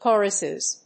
/ˈkɔrʌsɪz(米国英語), ˈkɔ:rʌsɪz(英国英語)/